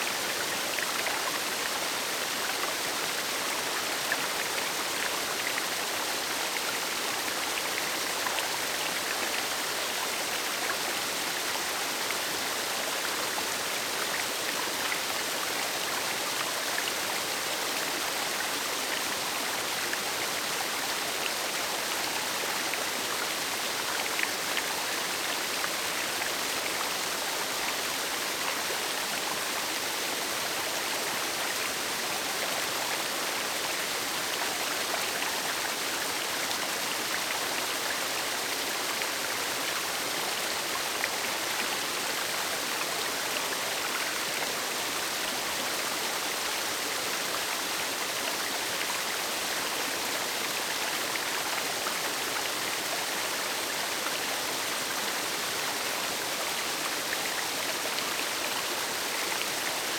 Waterfalls Rivers and Streams
River Loop.ogg